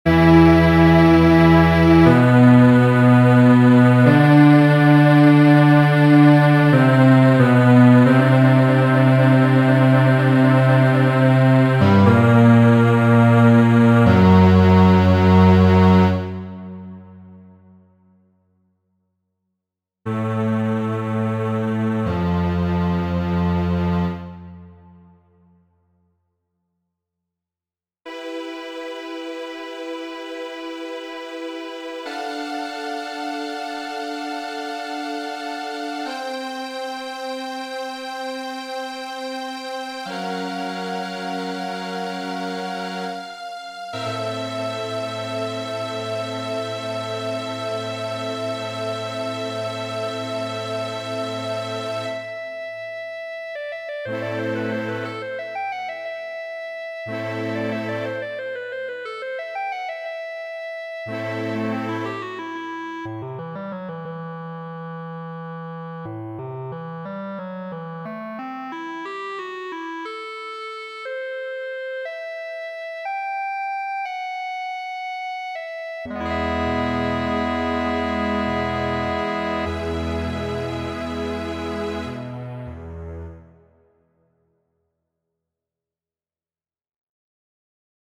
Mistera kaj ĉarma estas